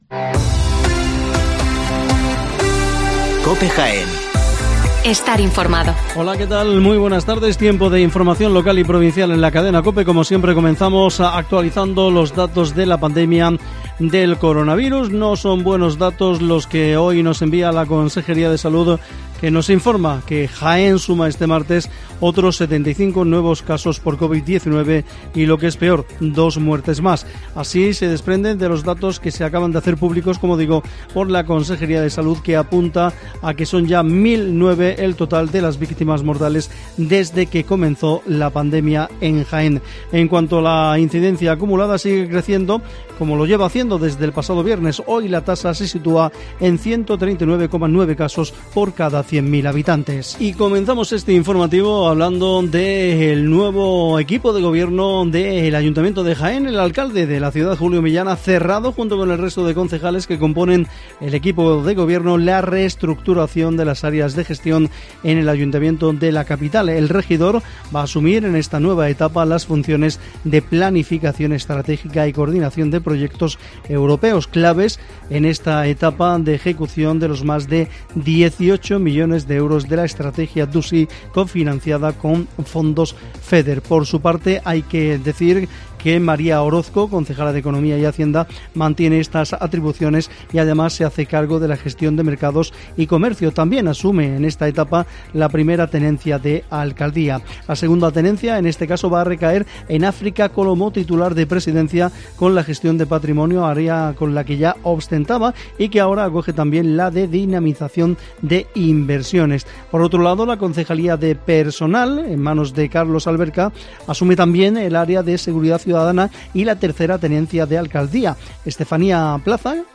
INFORMATIVO MEDIODÍA Las noticias de Jaén